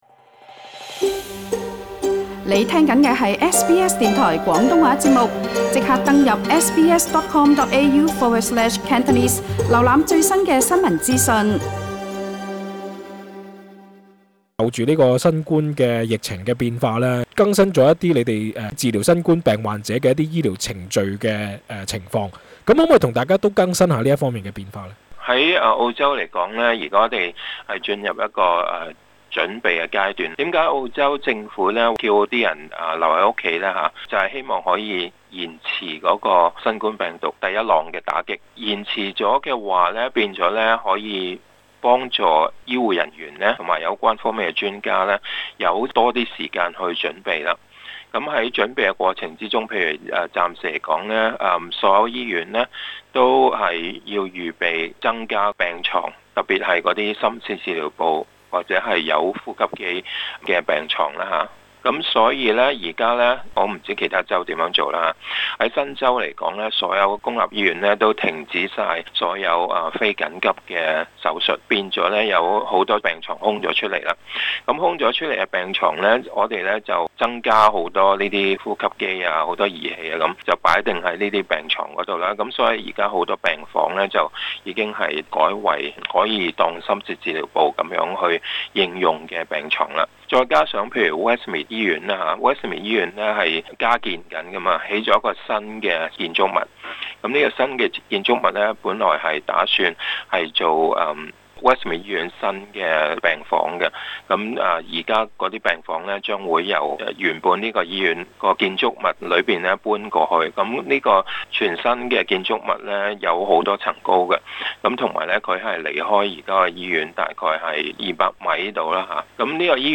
更多詳情，請留意足本錄音訪問。